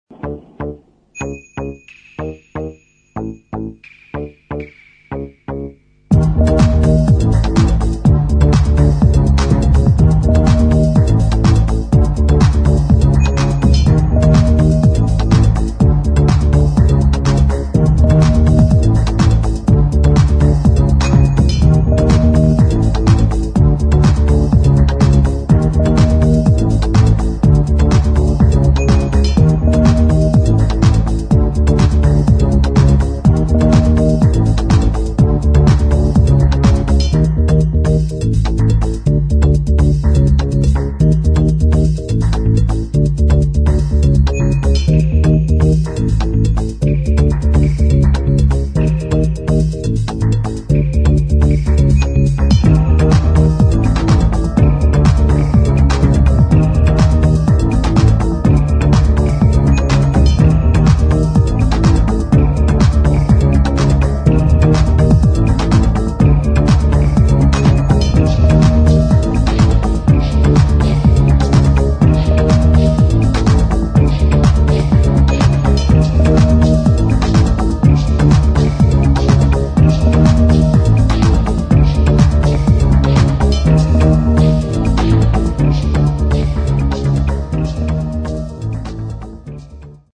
[ TECHNO / ELECTRONIC ]